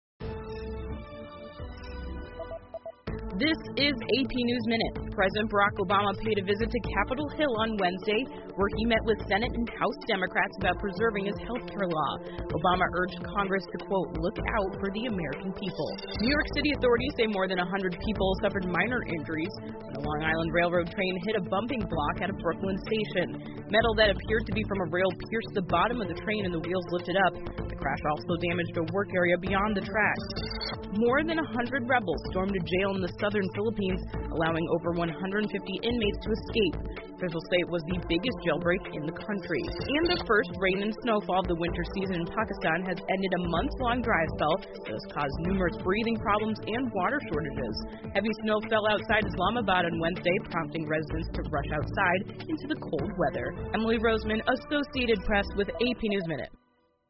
美联社新闻一分钟 AP 布鲁克林车站火车脱轨 听力文件下载—在线英语听力室